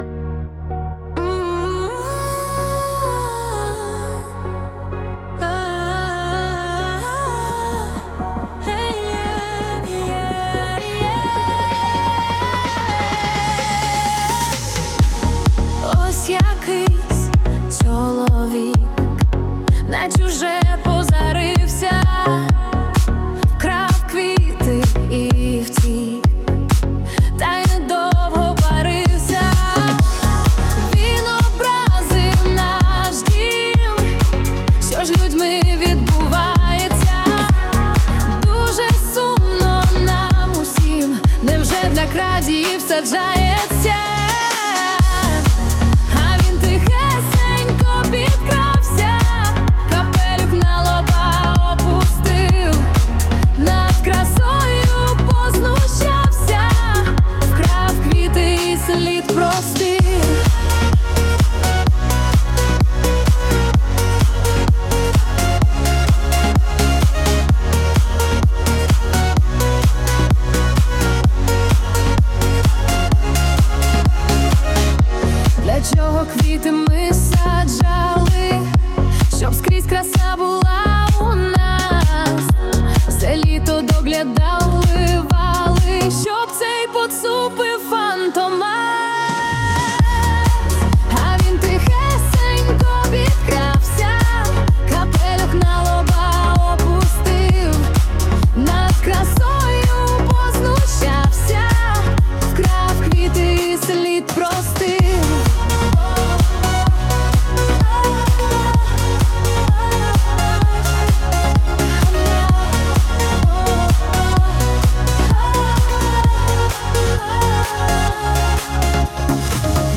ТИП: Пісня